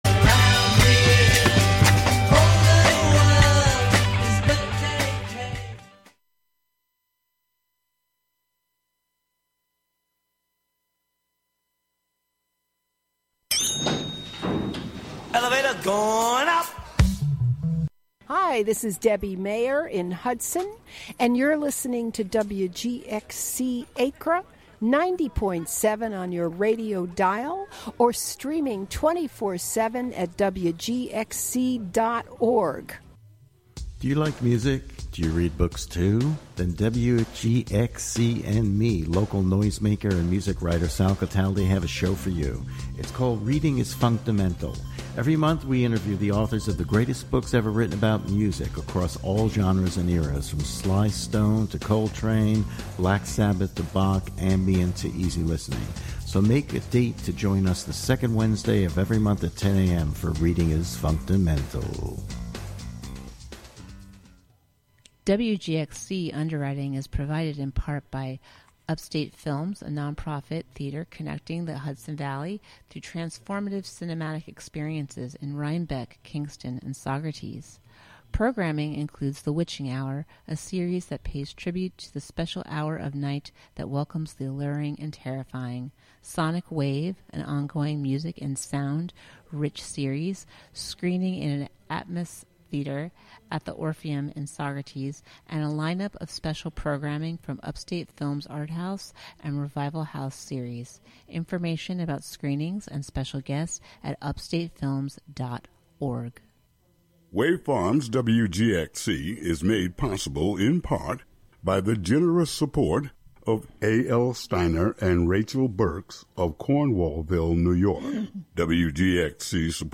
An upbeat music show featuring the American songbook as interpreted by contemporary artists as well as the jazz greats in a diverse range of genres live from WGXC's Catskill studio.